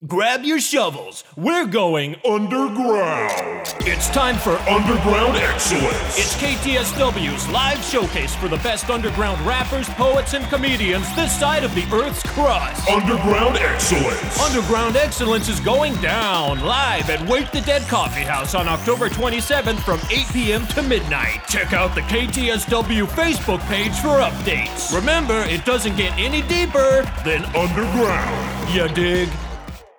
This is a promo for a local concert.